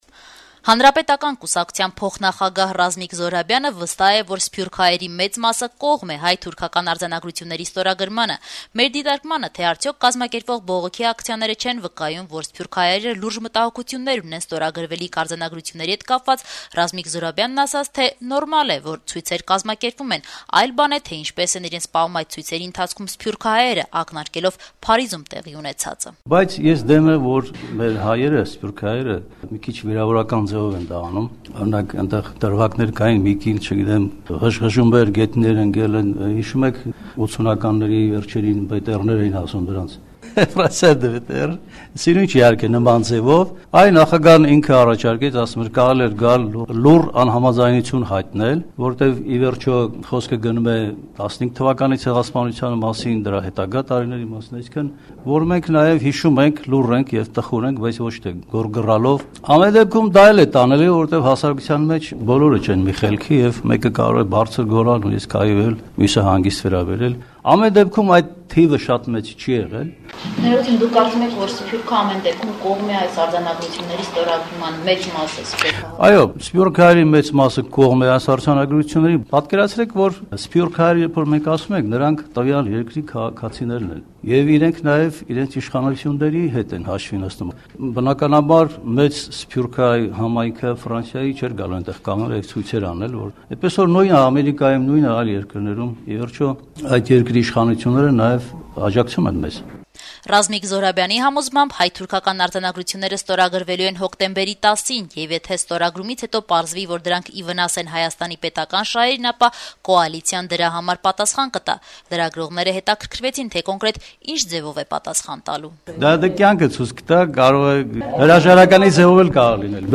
ՀՀԿ-ի փոխնախագահըի ասուլիսը